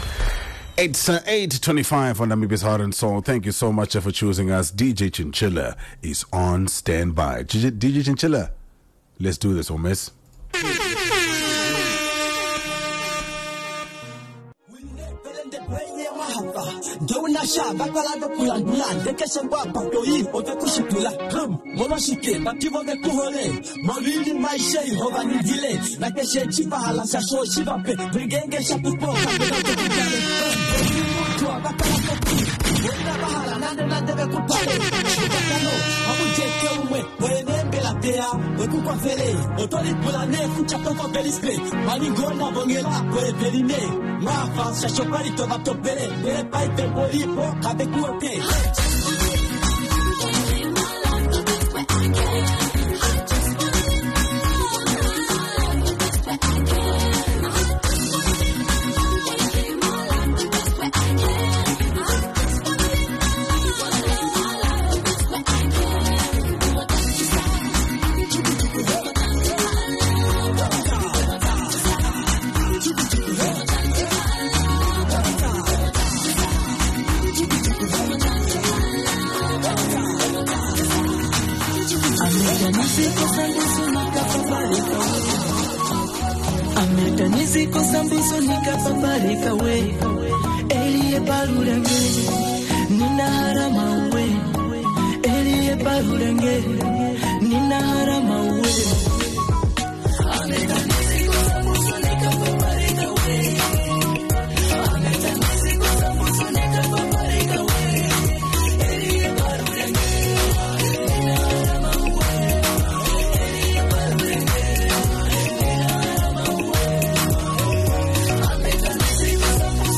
live mix